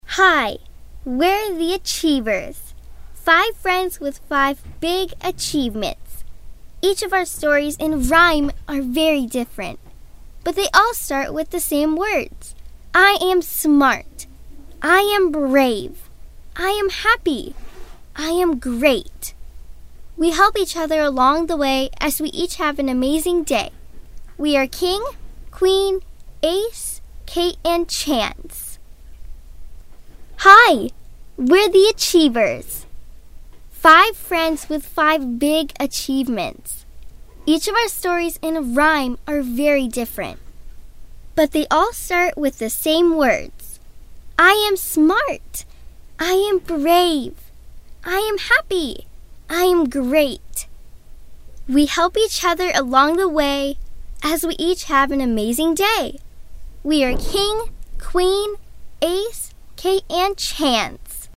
美式英语儿童亲切甜美 、女课件PPT 、工程介绍 、绘本故事 、动漫动画游戏影视 、250元/百单词女英144 美式英语 女童 10岁左右 亲切甜美